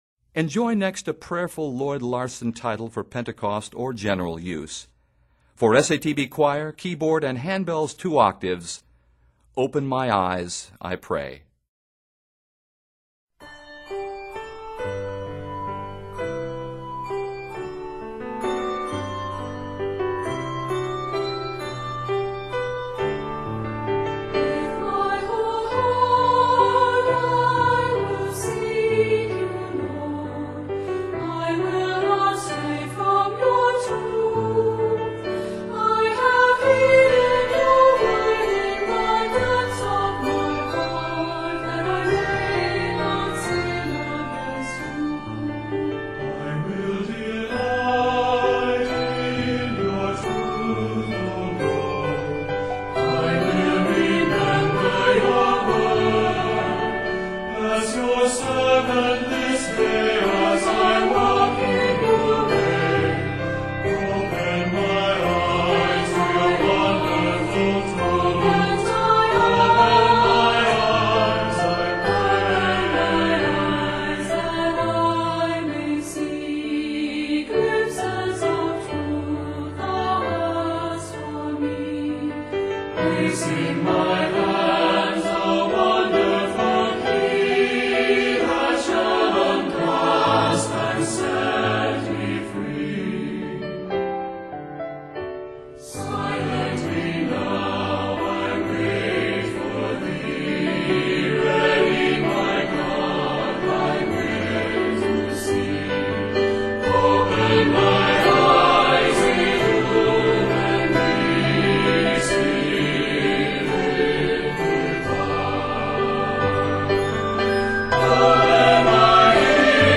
handbells, keyboard and choir
Arranged in G Major and A-Flat Major, measures total 73.